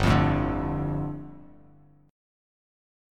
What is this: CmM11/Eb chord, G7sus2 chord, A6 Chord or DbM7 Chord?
G7sus2 chord